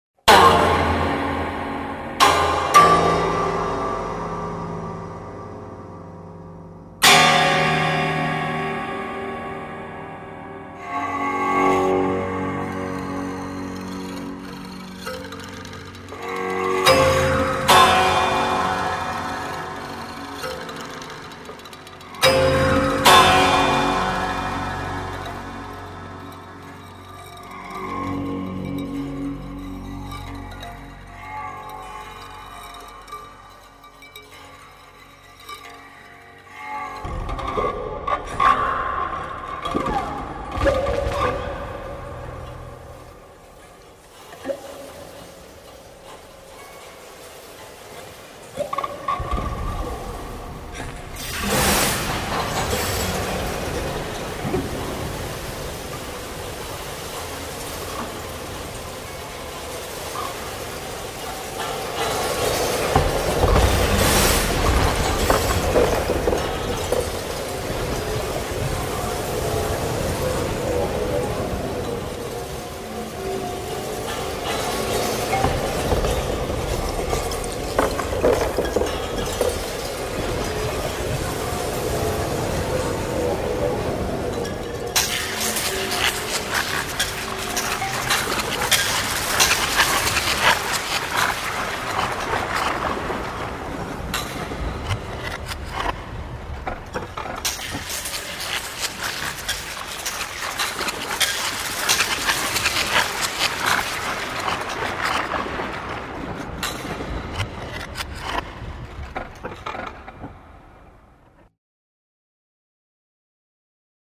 Music for original instruments and electronics
and he also plays prepared piano and Moog synthesizer.